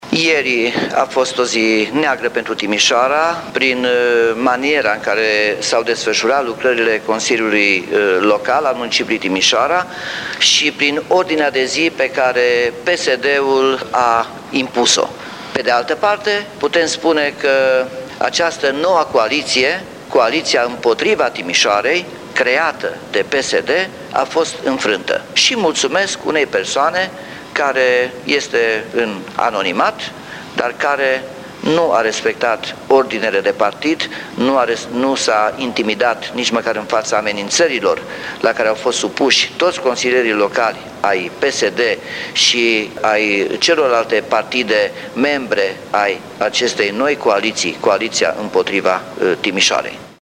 Liderul PNL Timis, Nicolae Robu a vorbit,sâmbătă, despre reuşita de a salva Timişoara, prin sufragiul exprimat în Consiliul Local.